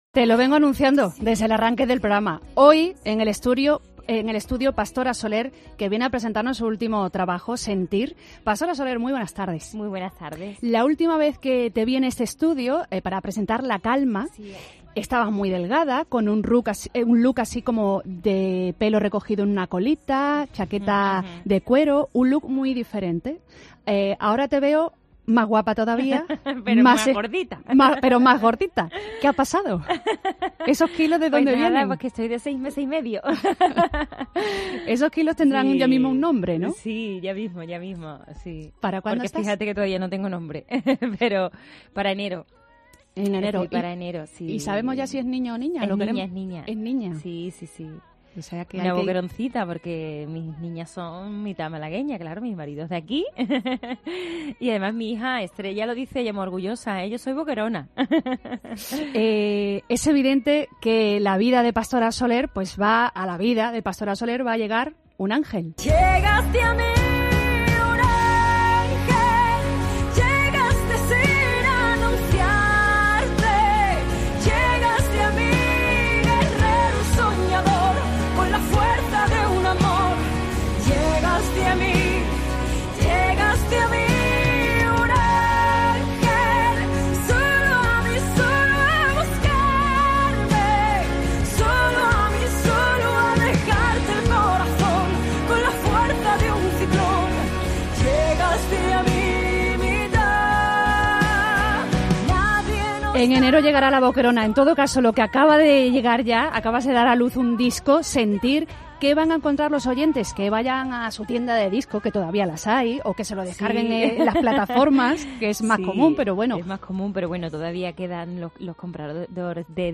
Entrevista de Pastora Soler en COPE Málaga